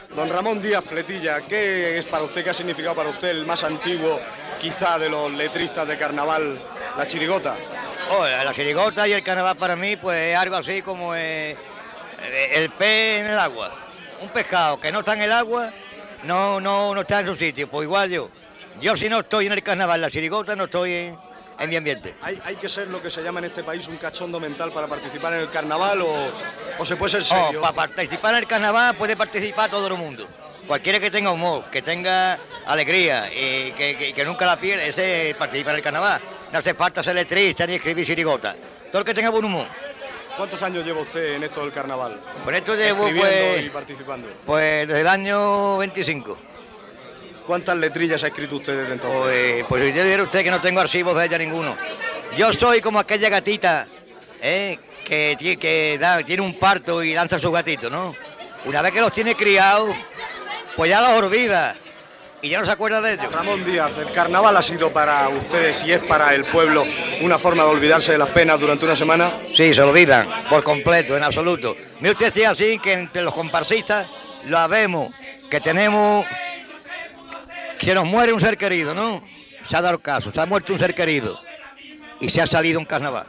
Entrevista
Entreteniment